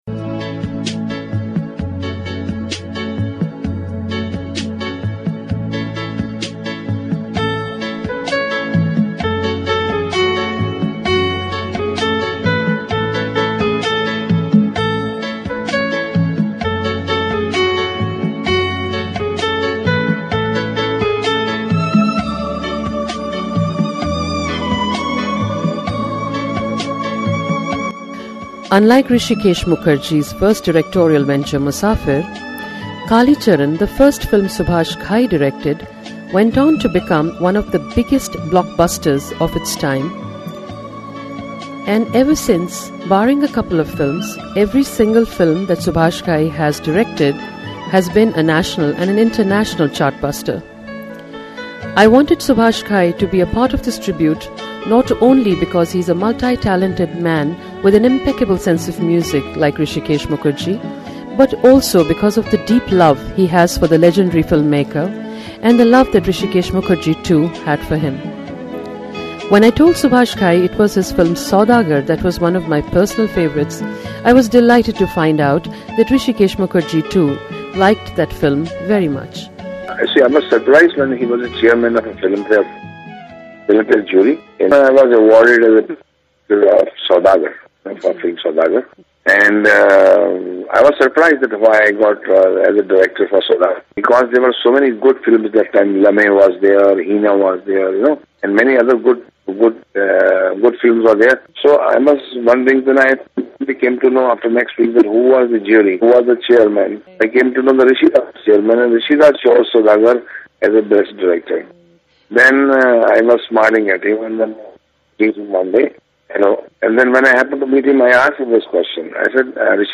Subhash Ghai in conversation
Iconic film director Subhash Ghai speaks of the reason why the late film maker Hrishikesh Mukherjee has a place not only in his heart but more. A candid conversation